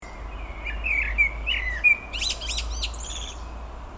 черный дрозд, Turdus merula
Administratīvā teritorijaBabītes novads
Skaits2 - 3
СтатусПоёт